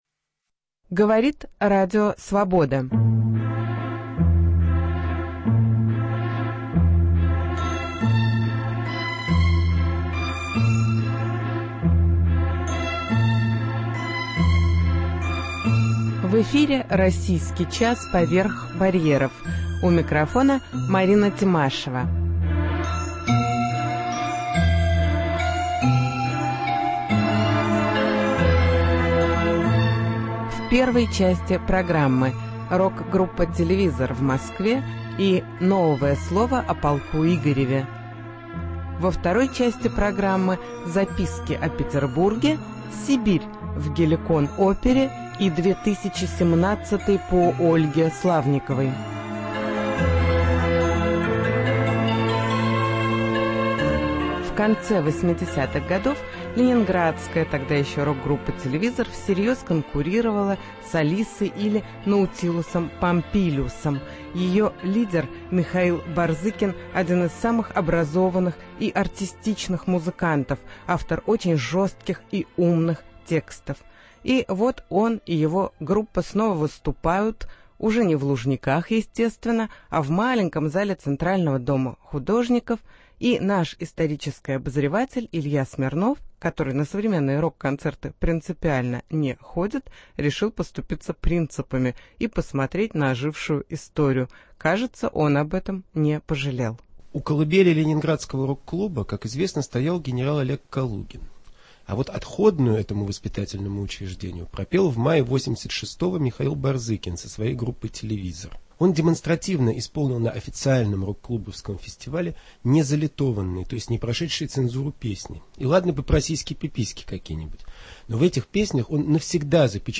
Выступления в Москве рок-группы "Телевизор"